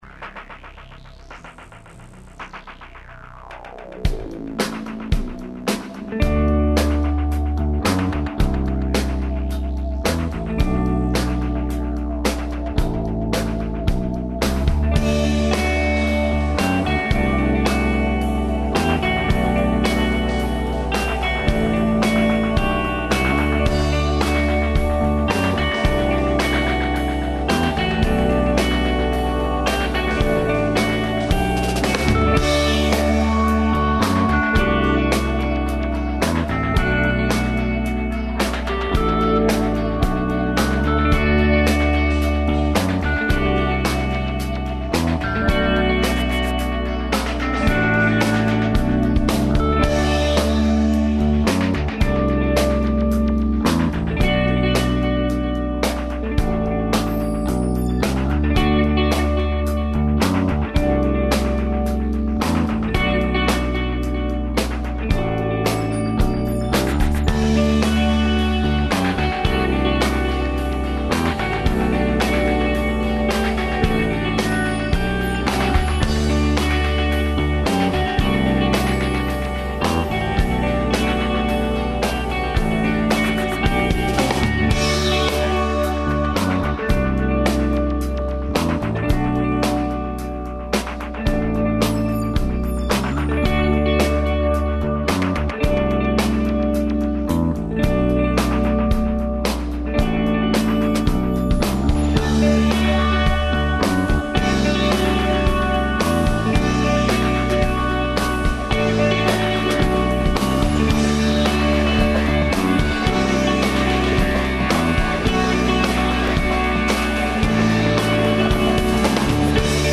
Данас говоримо о музици која се слуша у региону